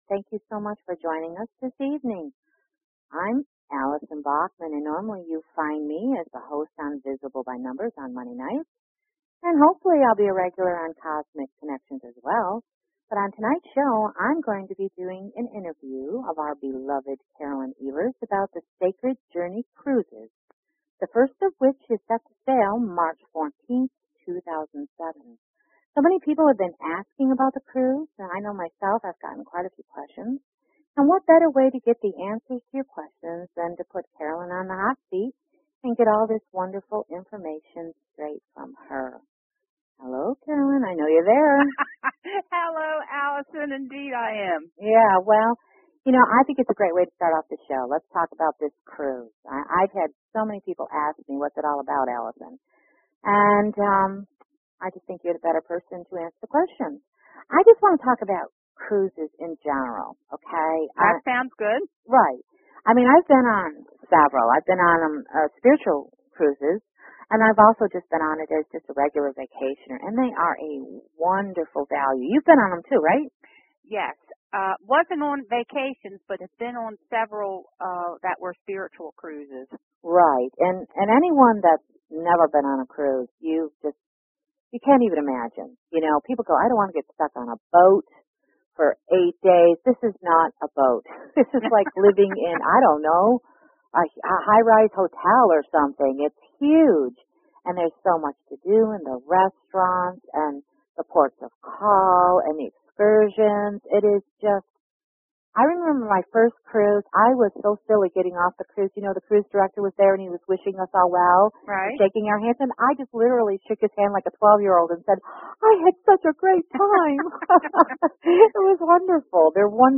Talk Show Episode, Audio Podcast, Cosmic_Connections and Courtesy of BBS Radio on , show guests , about , categorized as